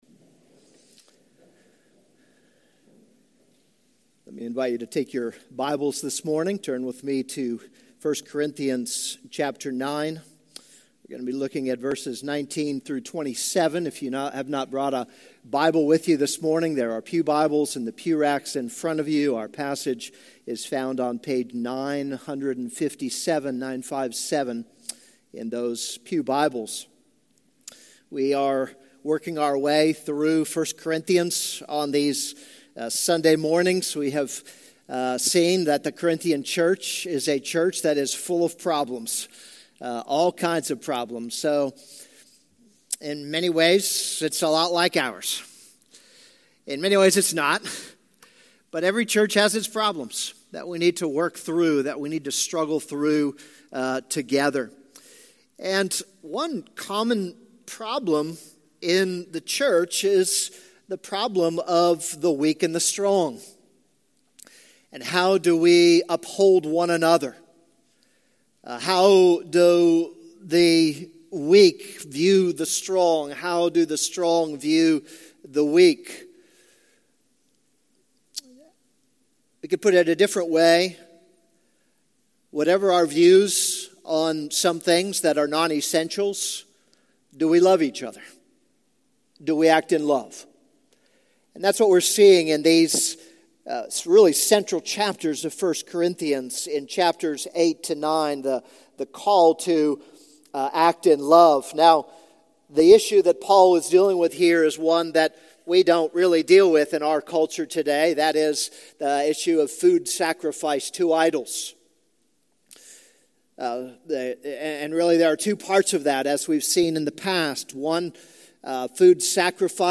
This is a sermon on 1 Corinthians 9:15-27.